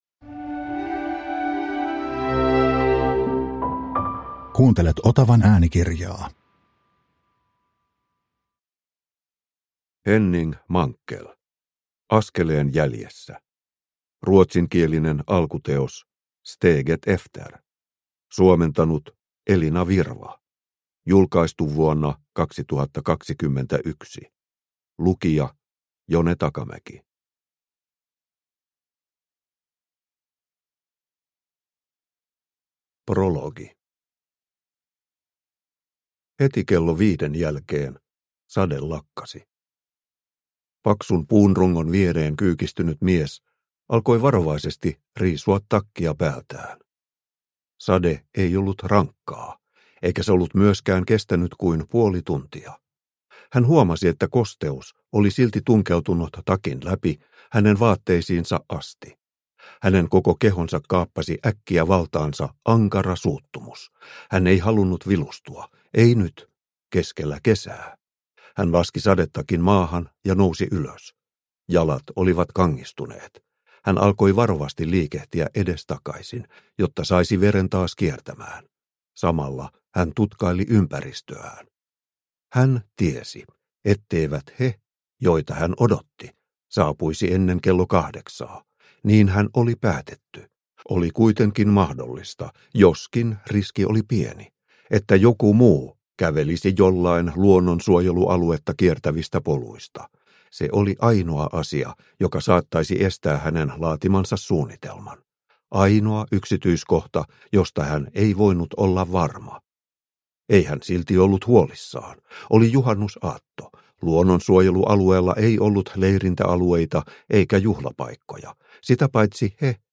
Askeleen jäljessä – Ljudbok – Laddas ner